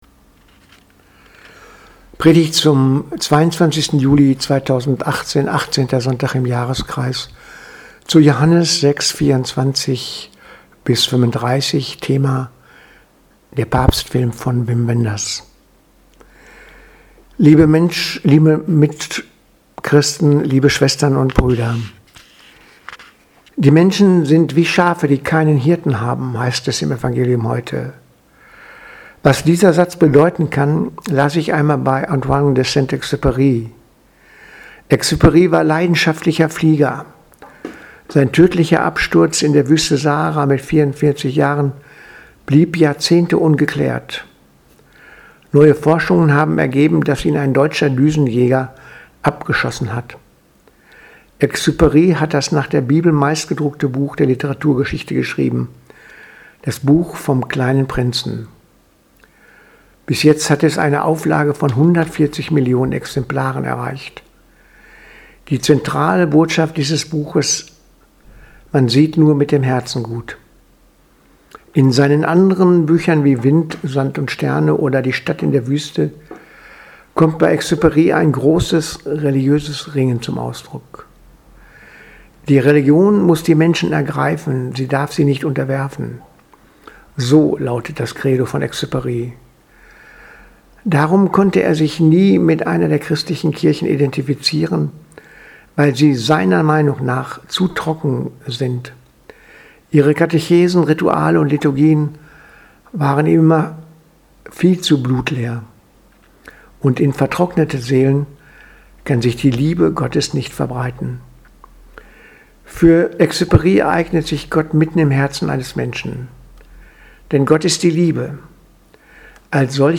Predigt vom 22.07.2018 – Papstfilm